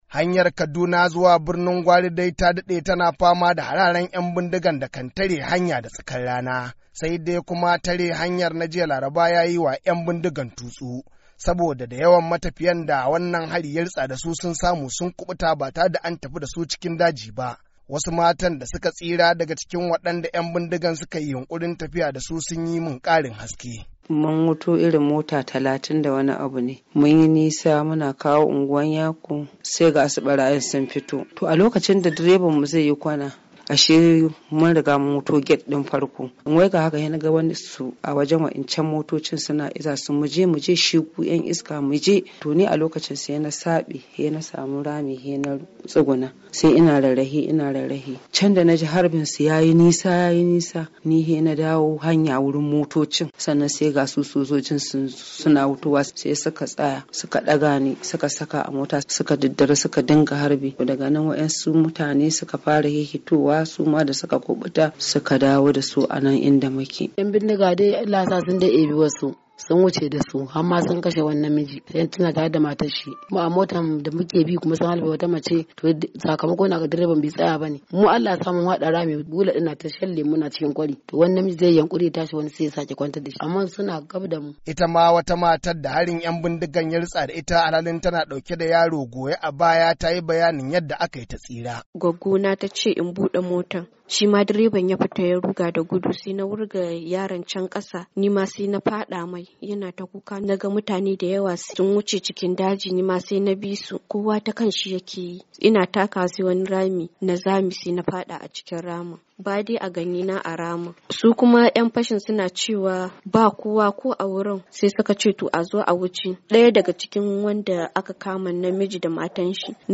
Wasu mata da su ka tsira wadanda su ka nemi mu sakaya sunansu, sun bayyana abin da ya faru.